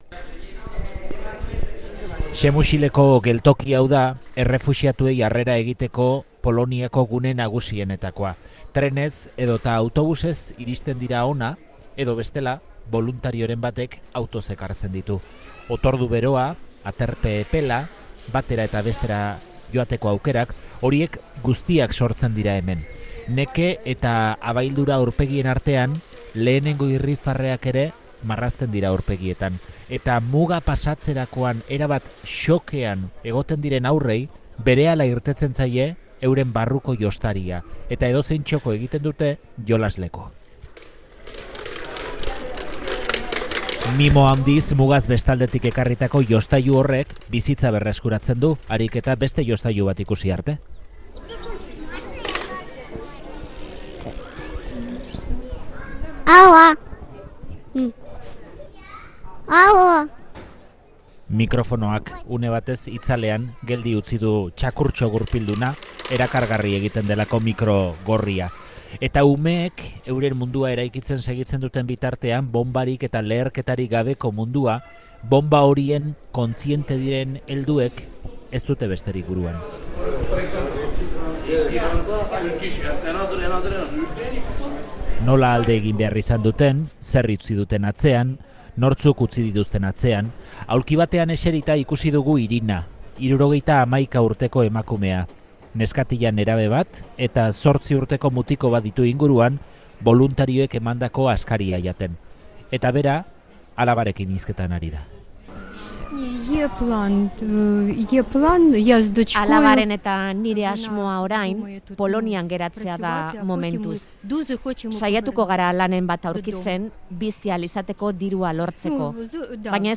Poloniako Przemysl herriko tren geltokian